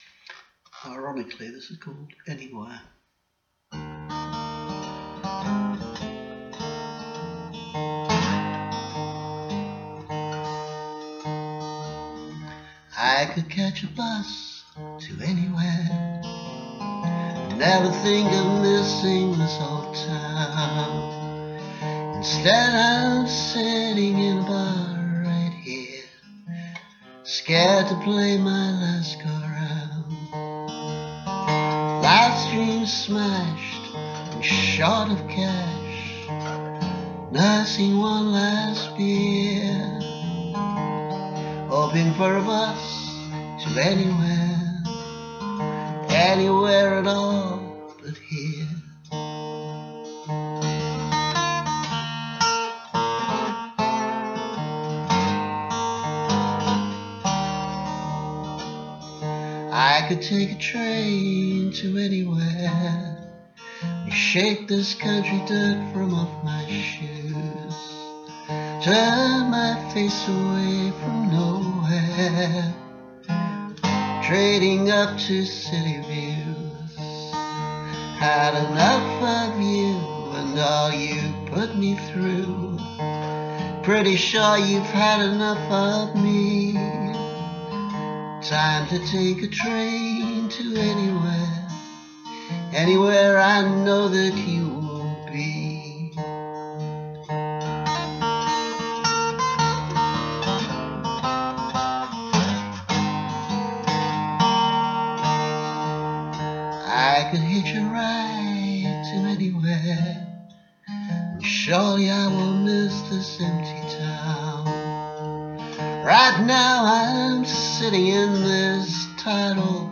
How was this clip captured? Remastered: Audio capture from a video: